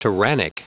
Prononciation du mot tyrannic en anglais (fichier audio)
Prononciation du mot : tyrannic